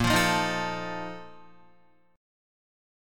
A#M7sus4 chord